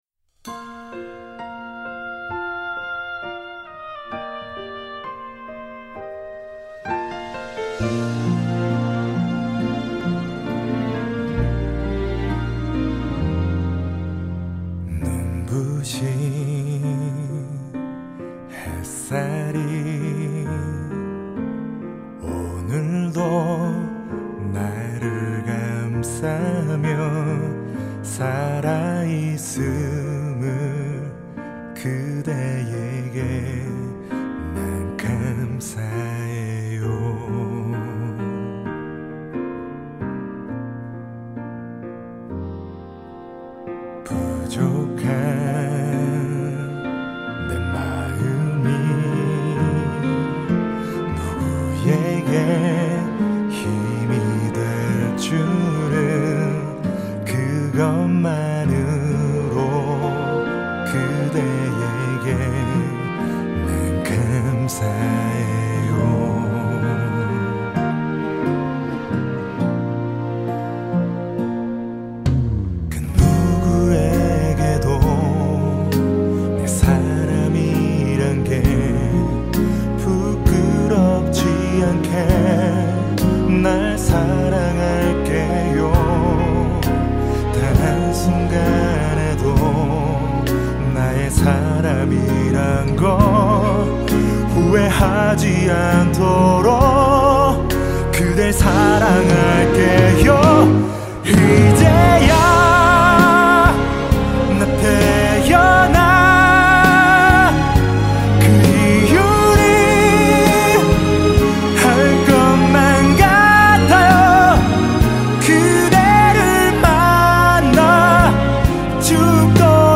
주로 발라드 가수로서 사람들에게 인식되고 있고.